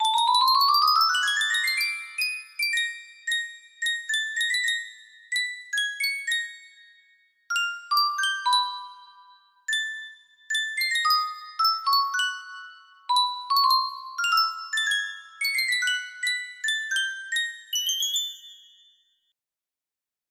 toreador march music box melody
Full range 60